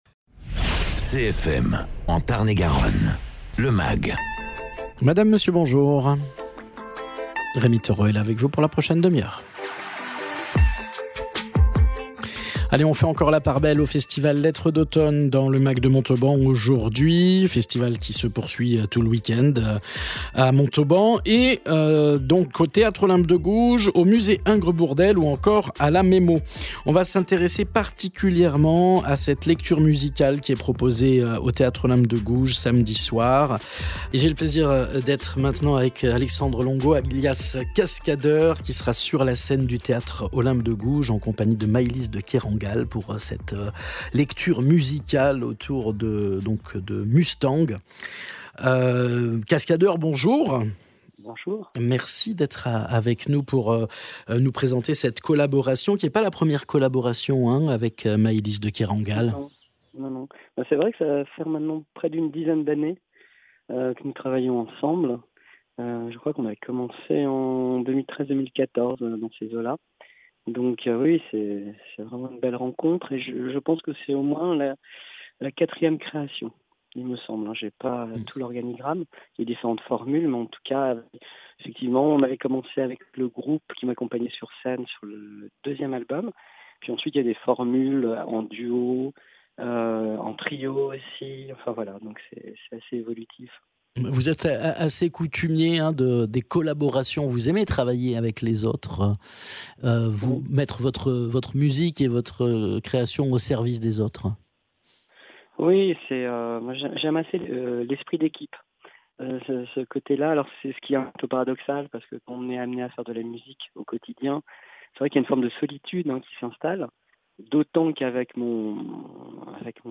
Invité(s) : Cascadeur, Auteur compositeur interprète...